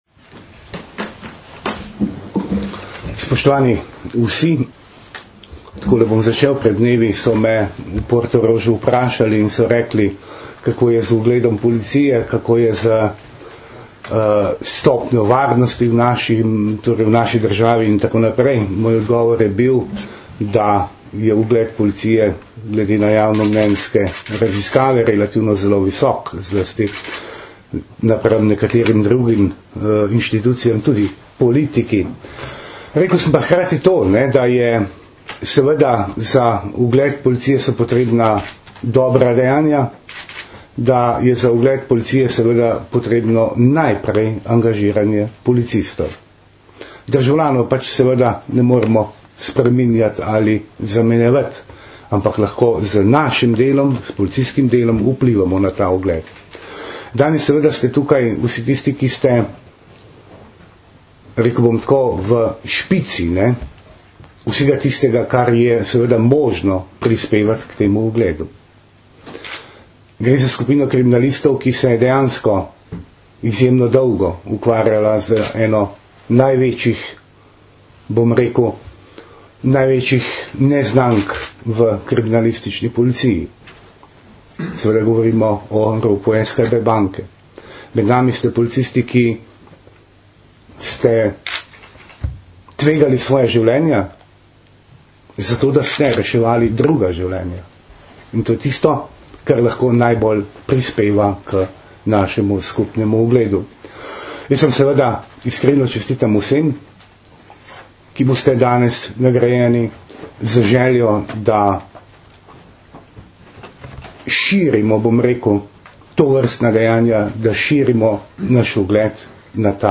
Generalni direktor policije Janko Goršek in minister za notranje zadeve dr. Vinko Gorenak sta danes, 13. junija 2012, v Policijski akademiji v Tacnu podelila 22 medalj policije za požrtvovalnost. Oba sta zbrane tudi nagovorila.
Zvočni posnetek nagovora ministra za notranje zadeve (mp3)